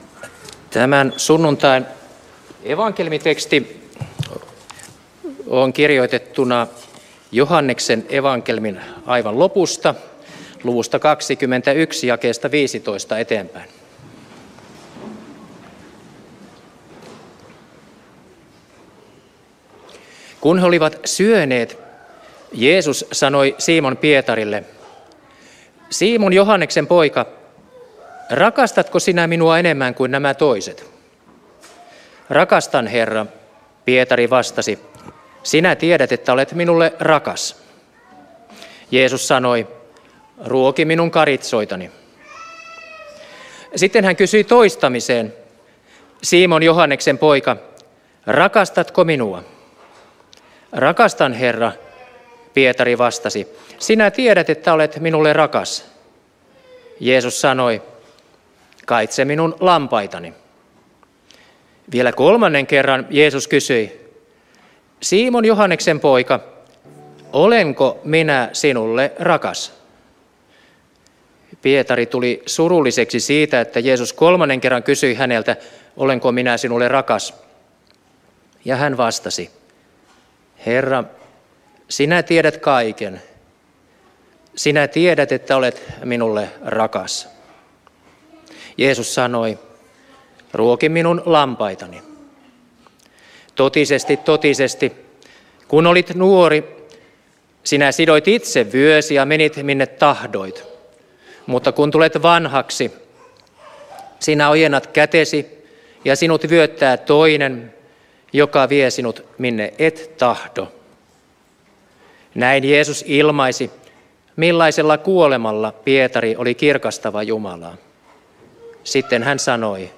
saarna Turun Luther-kirkossa 2. sunnuntaina pääsiäisestä Tekstinä Joh. 21:15–19